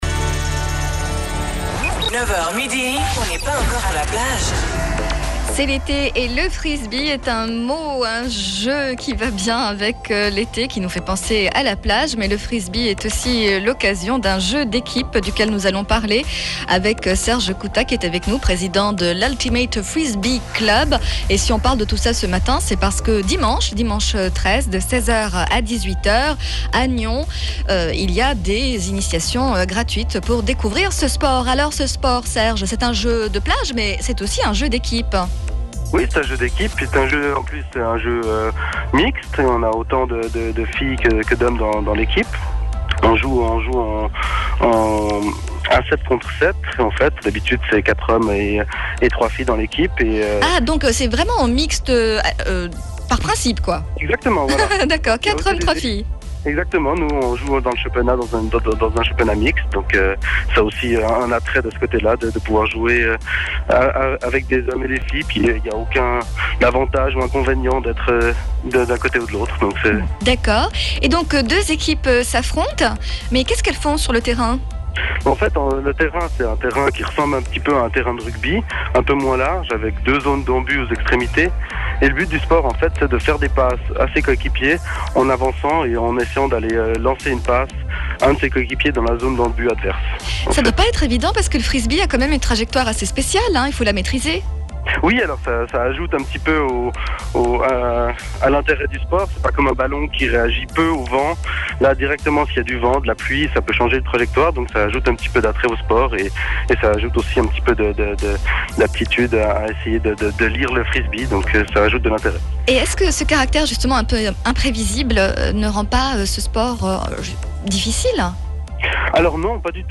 Interview One FM 2008 - portes ouvertes